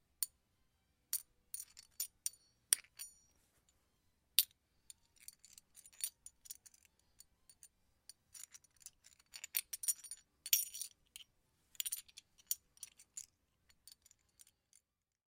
处理金钱 " 处理4枚硬币
描述：处理4个硬币（小/中/大尺寸） （录制于AT4033a的工作室） 声音来自ContadordeHistóriasPodcast＆amp